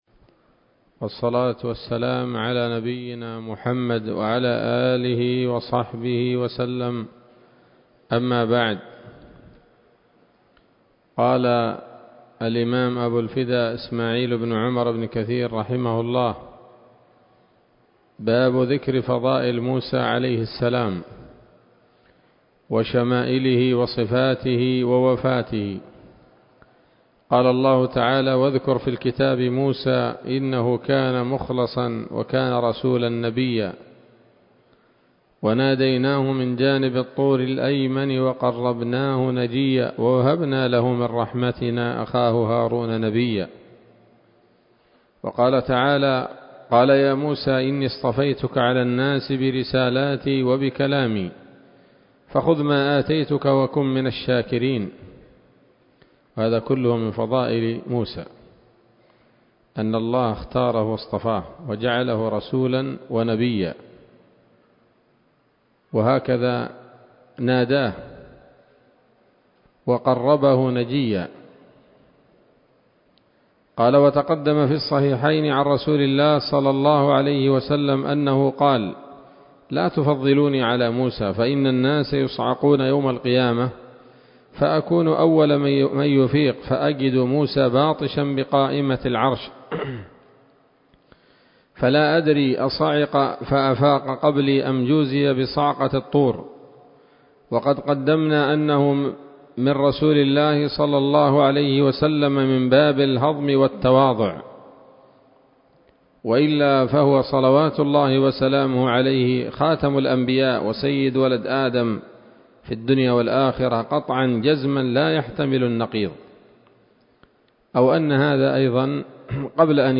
‌‌الدرس الخامس بعد المائة من قصص الأنبياء لابن كثير رحمه الله تعالى